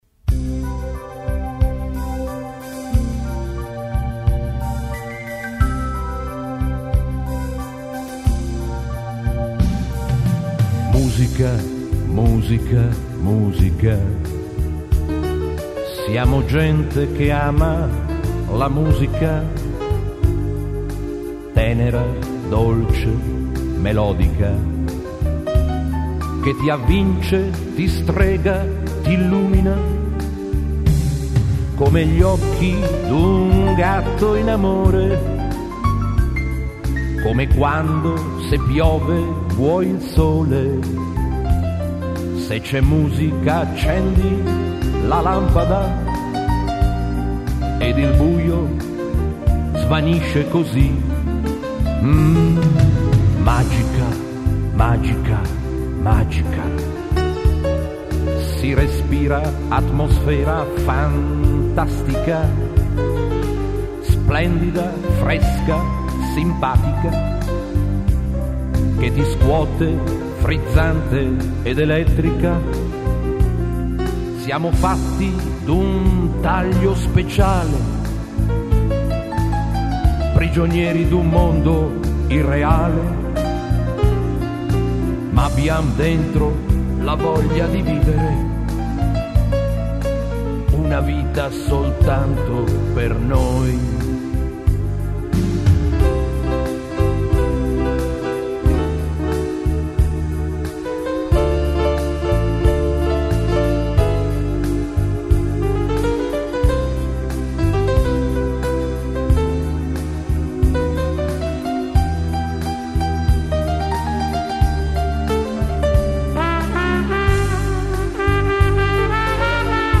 nella sala di registrazione